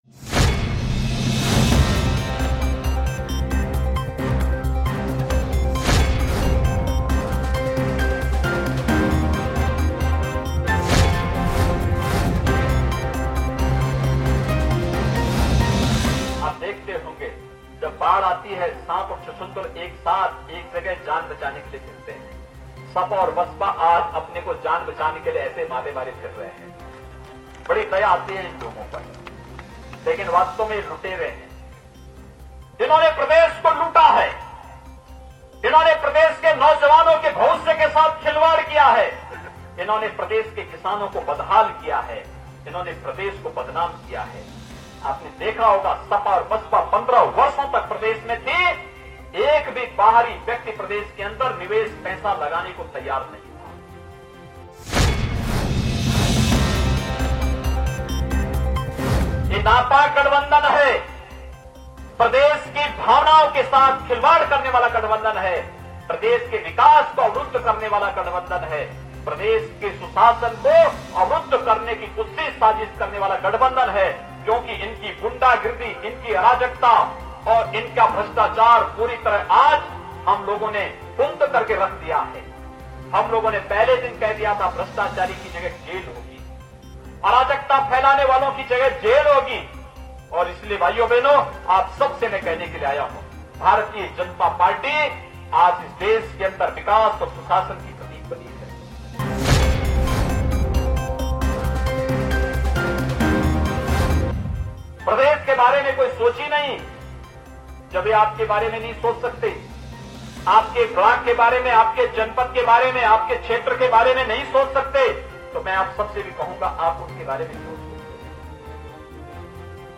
News Report / साँप और छुछुंदर की जोड़ी है SP और BSP